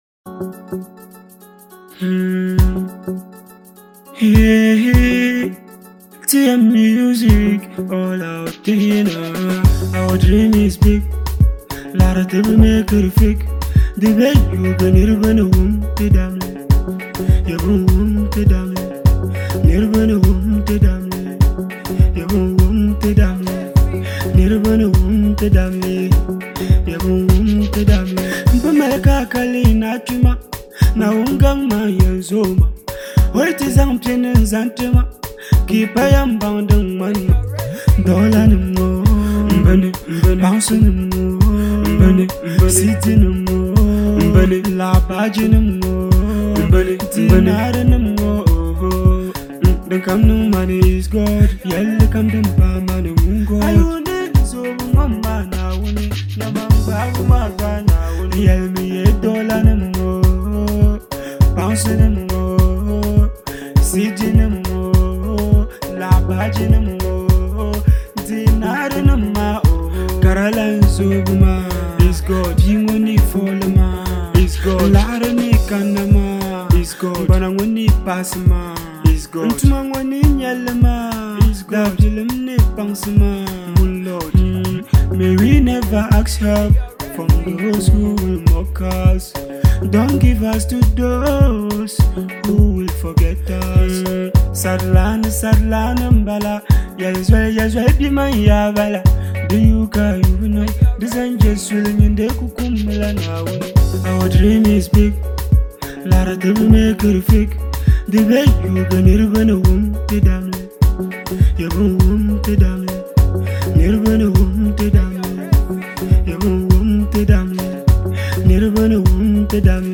With its smooth rhythm and heartfelt expression